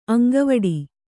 ♪ aŋgavaḍi